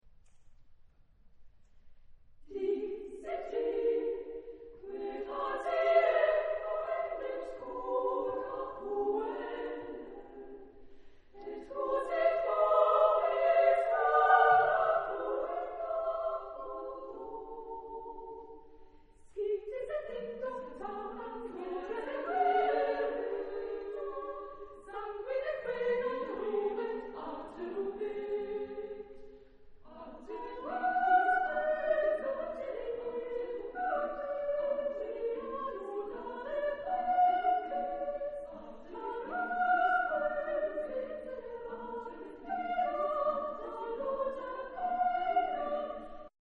Genre-Style-Forme : Madrigal ; Cycle ; Profane
Type de choeur : SSA  (3 voix égales de femmes )
Tonalité : libre